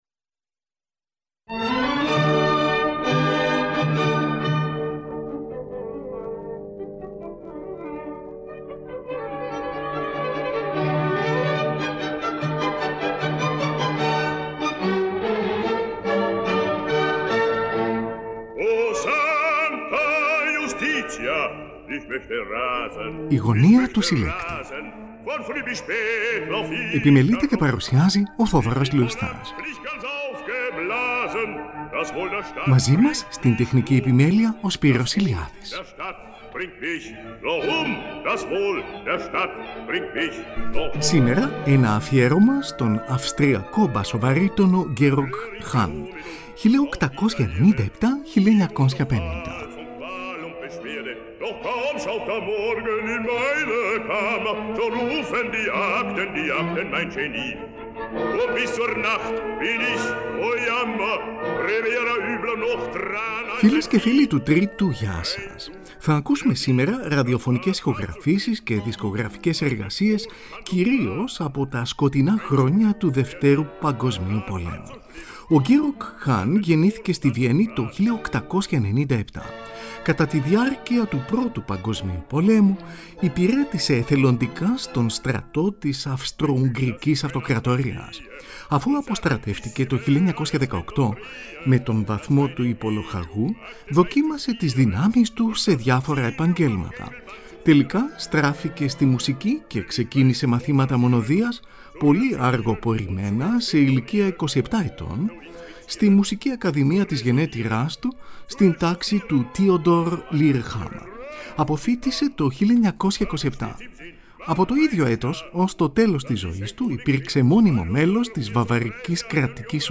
AΦΙΕΡΩΜΑ ΣΤΟΝ ΑΥΣΤΡΙΑΚΟ ΜΠΑΣΟΒΑΡΥΤΟΝΟ GEORG HANN (1897-1950)
Ακούγονται αποσπάσματα από όπερες των Wolfgang Amadeus Mozart, Ludwig van Beethoven, Otto Nicolai, Carl Maria von Weber και Richard Wagner.